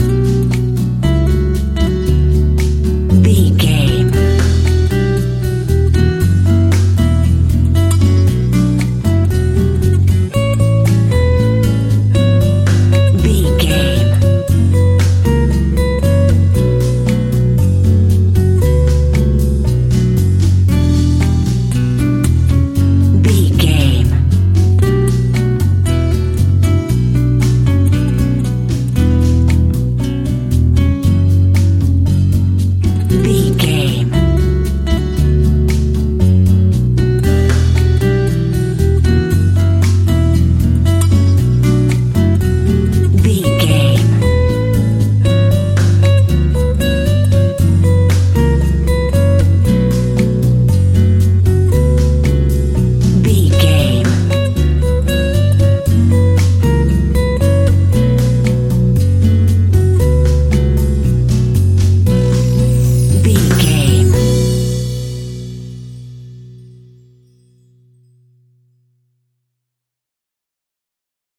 An exotic and colorful piece of Espanic and Latin music.
Aeolian/Minor
romantic
maracas
percussion spanish guitar
latin guitar